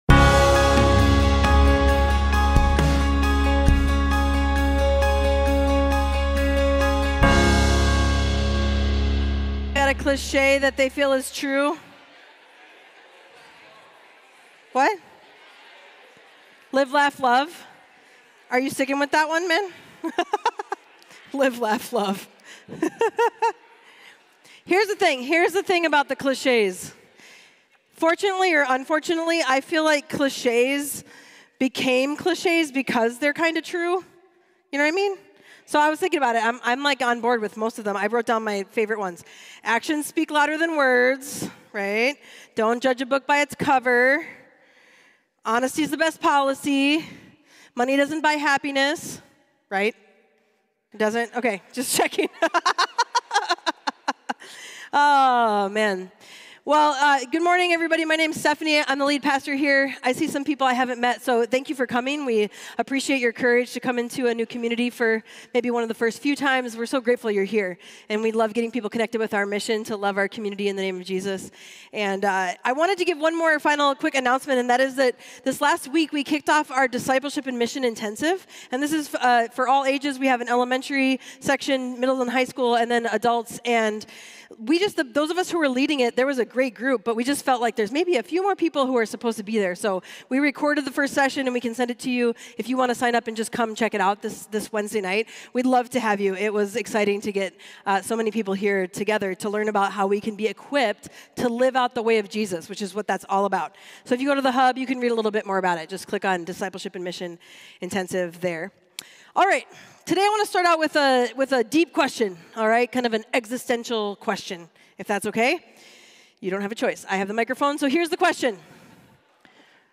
Mill City Church Sermons The Good Life Belongs to the Poor in Spirit Sep 25 2024 | 00:36:50 1x 00:00 / 00:36:50 Subscribe Share RSS Feed Share Link Embed